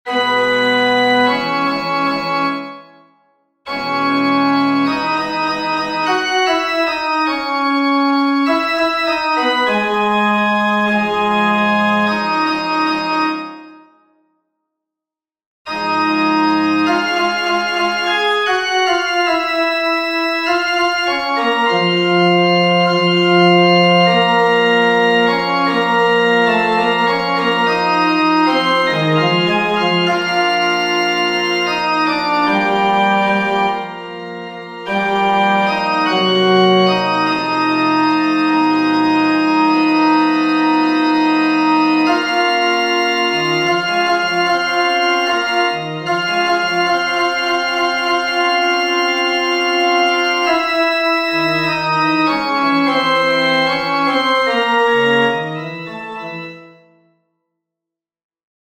FF:HV_15b Collegium male choir
Pensistum-1T.mp3